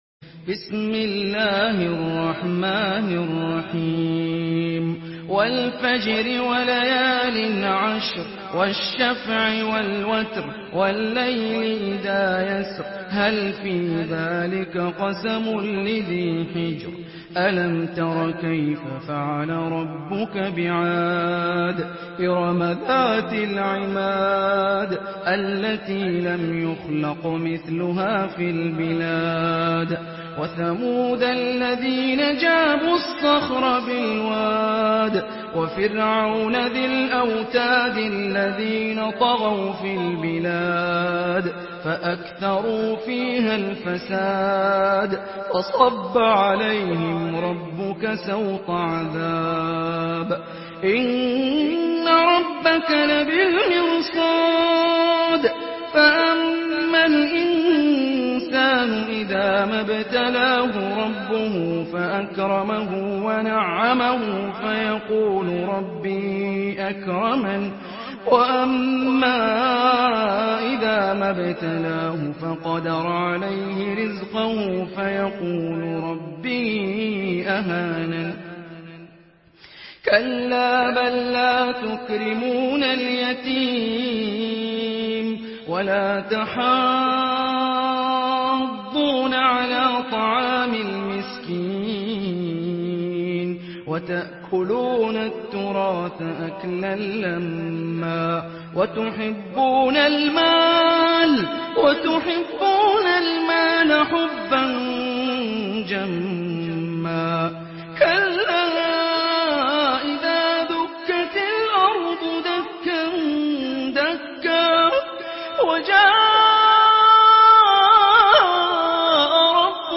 Surah আল-ফাজর MP3 in the Voice of Hani Rifai in Hafs Narration
Murattal Hafs An Asim